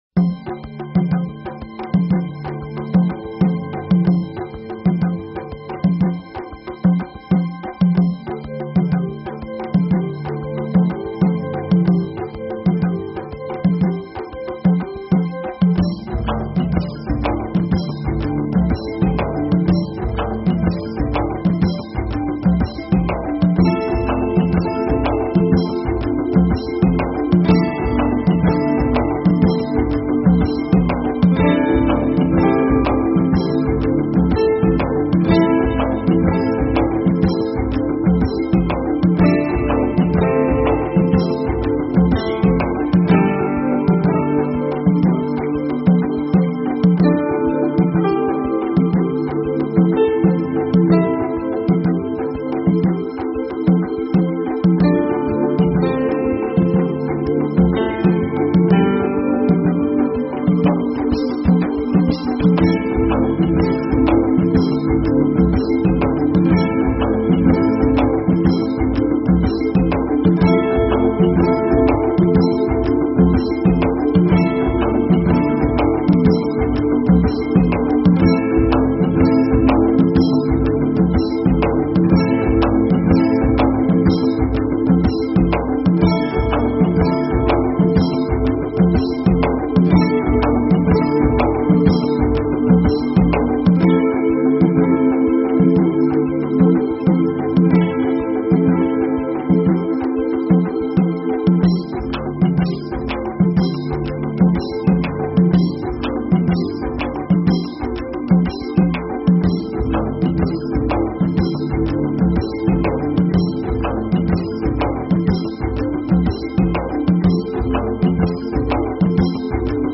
Talk Show Episode, Audio Podcast, DreamPath and Courtesy of BBS Radio on , show guests , about , categorized as
Comedy, music, and a continuous weaving of interviews and story telling (DreamPath style) covering numerous alternative thought topics.